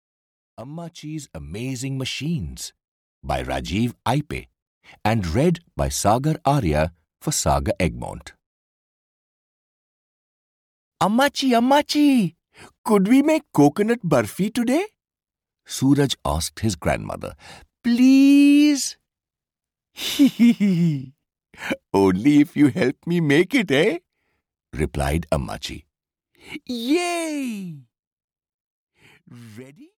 Ammachi's Amazing Machines (EN) audiokniha
Ukázka z knihy